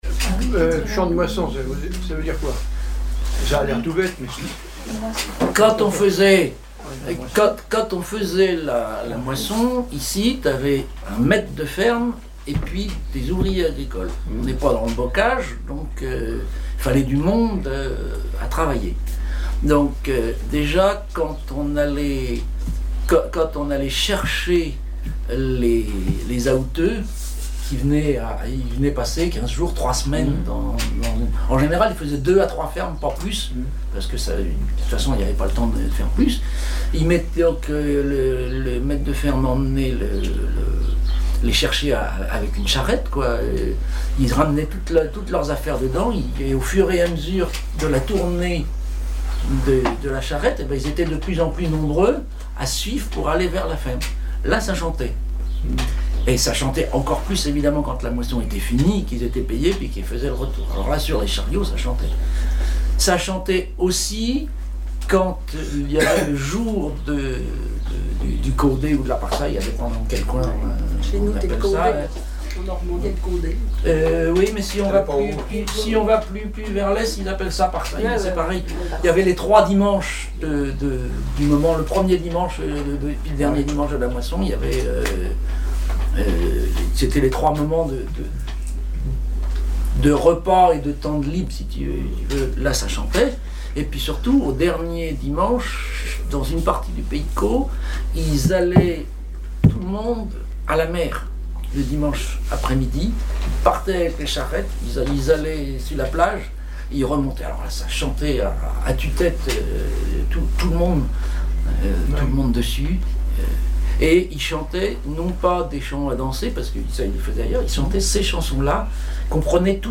Chansons et commentaires
Catégorie Témoignage